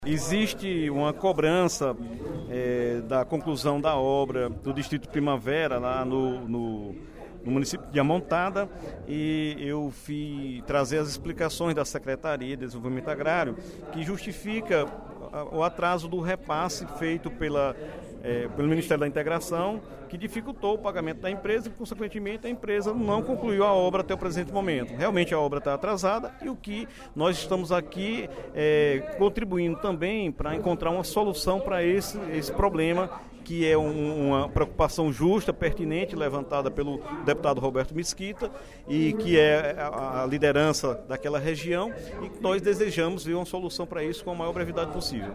O deputado Dr. Santana (PT) prestou esclarecimentos, no primeiro expediente da sessão plenária desta quinta-feira (30/06), sobre a paralisação da obra do sistema de abastecimento de água do distrito de Primavera, no município de Amontada.